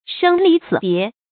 注音：ㄕㄥ ㄌㄧˊ ㄙㄧˇ ㄅㄧㄝ ˊ
生離死別的讀法